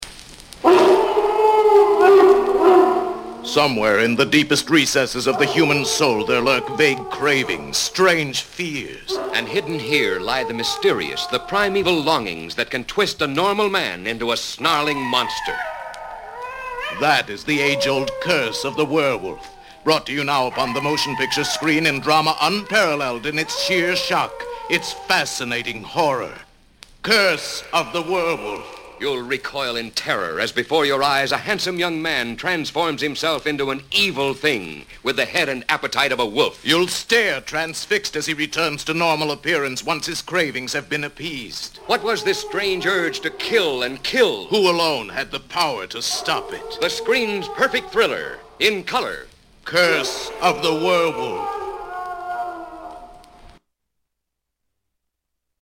Howl With Some Werewolf Movie Radio Spots